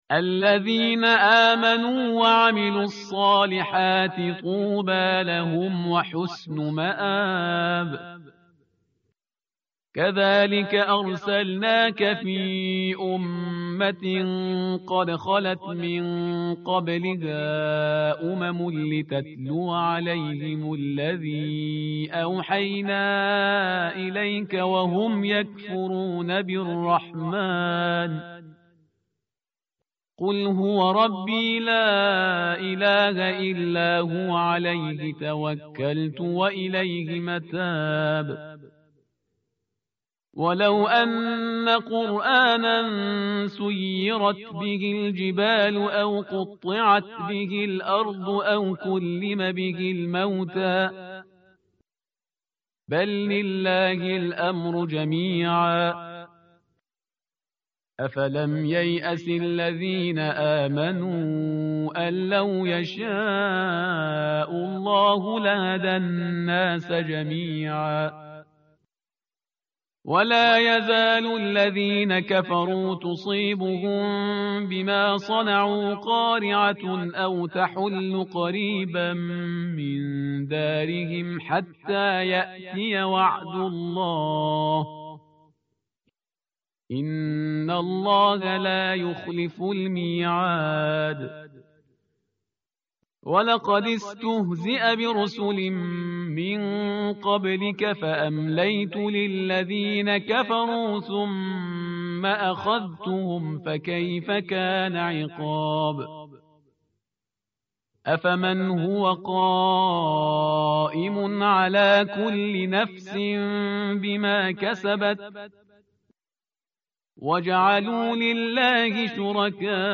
متن قرآن همراه باتلاوت قرآن و ترجمه
tartil_parhizgar_page_253.mp3